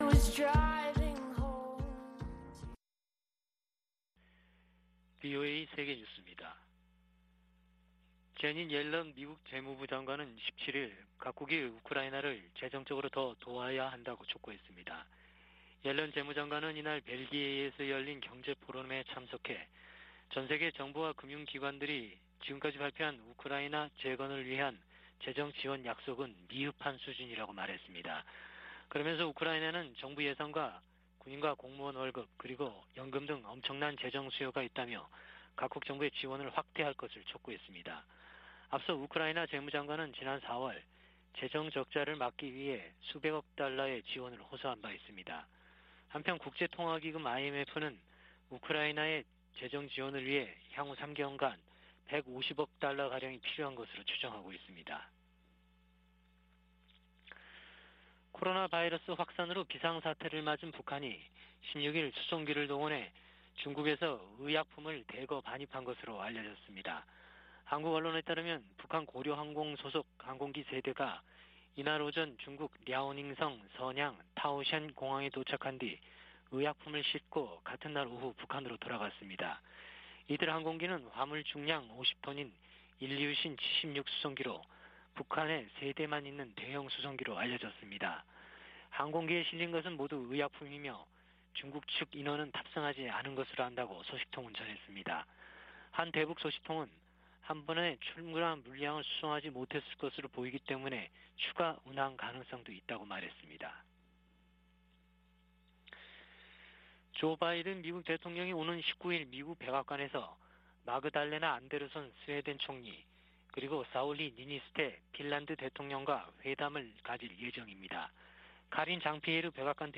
VOA 한국어 아침 뉴스 프로그램 '워싱턴 뉴스 광장' 2022년 5월 18일 방송입니다. 북한은 연일 신종 코로나바이러스 감염증 발열자가 폭증하는 가운데 한국 정부의 방역 지원 제안에 답하지 않고 있습니다. 세계보건기구가 북한 내 급속한 코로나 확산 위험을 경고했습니다. 북한의 IT 기술자들이 신분을 숨긴 채 활동하며 거액의 외화를 벌어들이고 있다고 미국 정부가 지적했습니다.